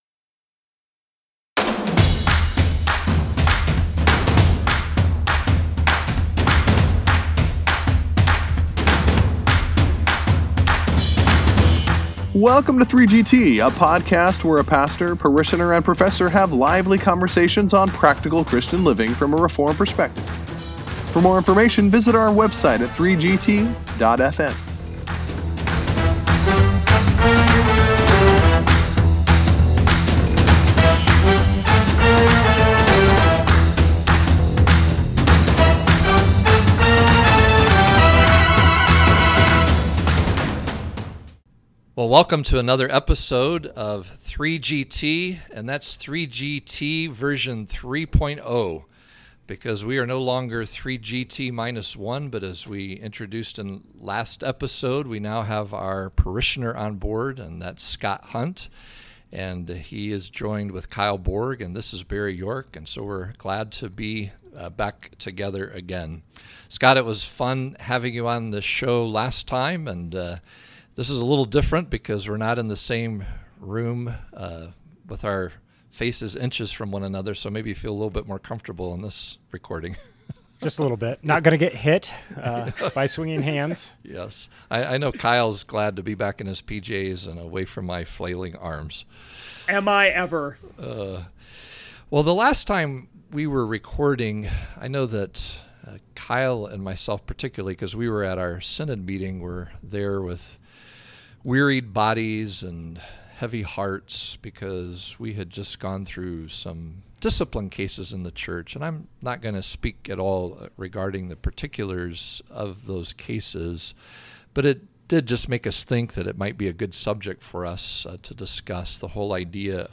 As the pastor and professor continue to rejoice over having a new parishioner in the fold, the guys interact over the issue of church discipline.